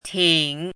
chinese-voice - 汉字语音库
ting3.mp3